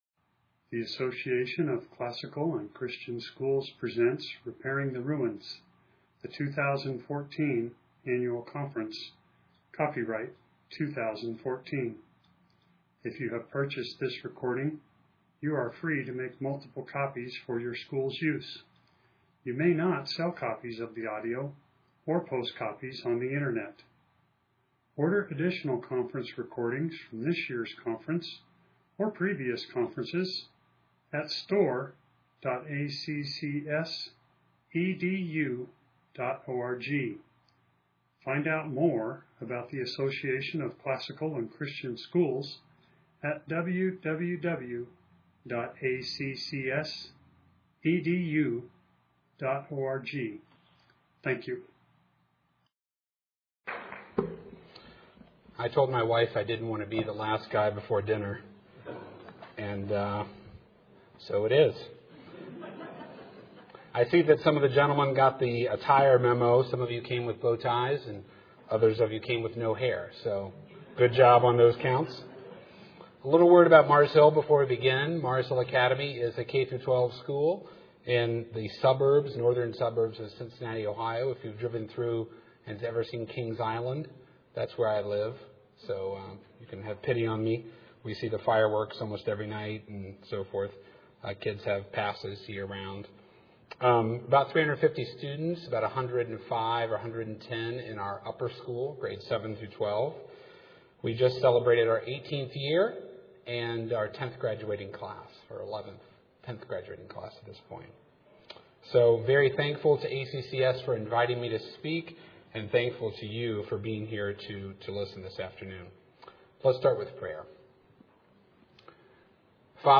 2014 Leaders Day Talk | 1:02:08 | Culture & Faith